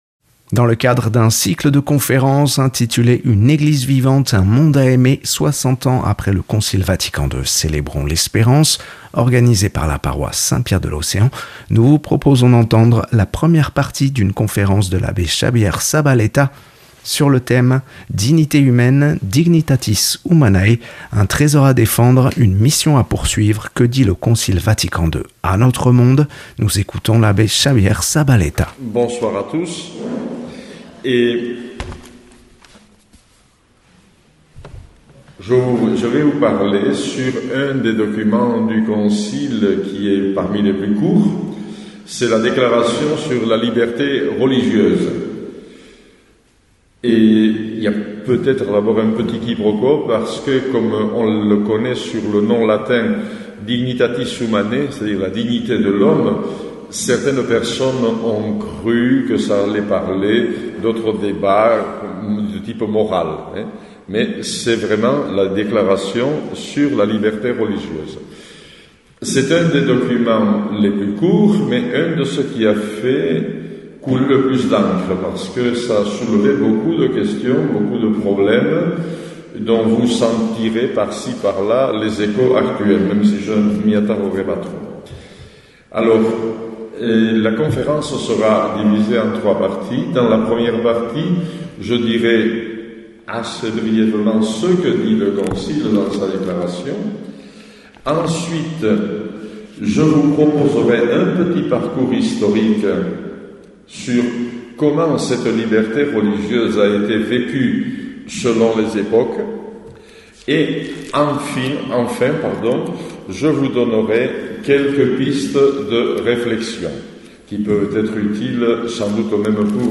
Que dit le Concile Vatican II à notre monde ? Une conférence
(Paroisse Saint Pierre de l’Océan)